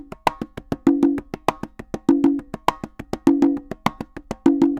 Congas_Salsa 100_1.wav